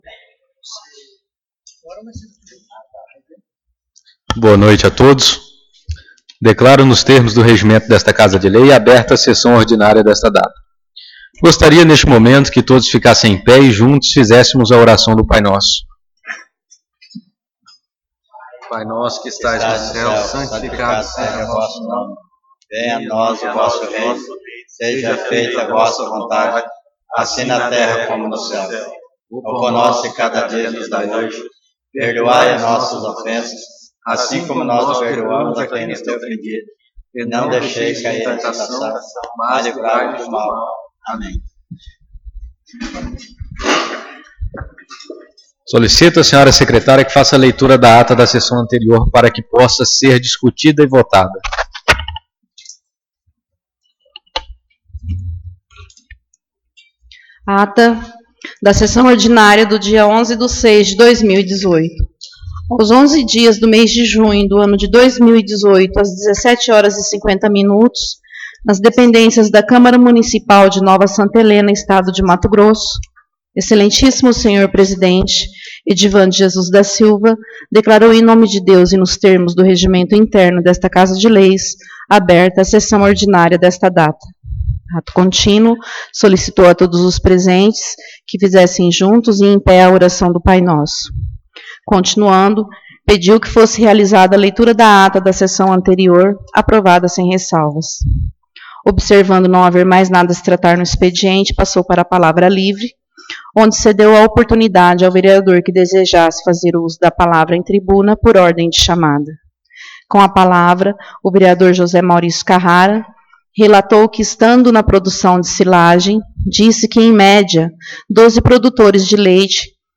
Sessão Ordinária 18/06/2018